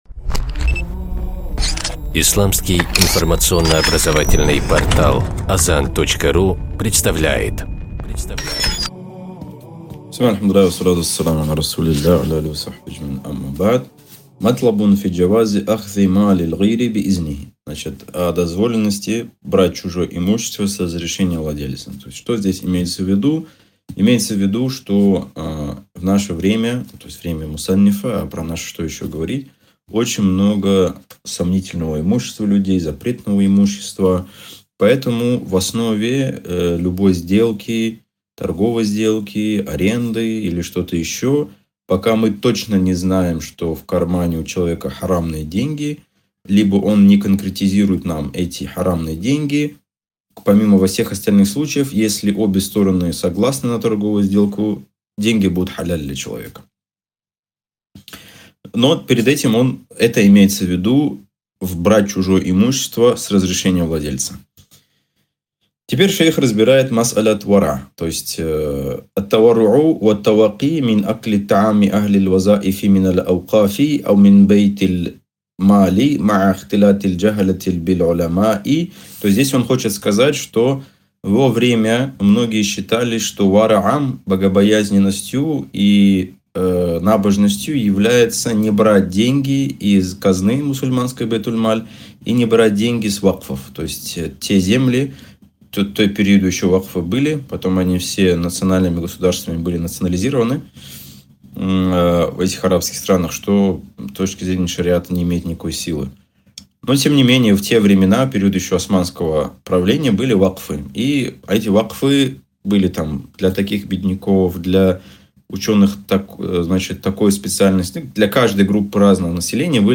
Цикл уроков: «Харам и халяль» по книге «Хазр валь-Ибаха»